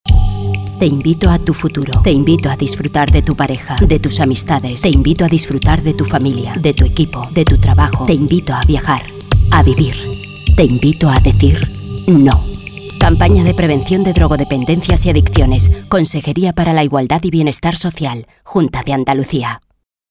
2. Emisión de cuñas de radio: -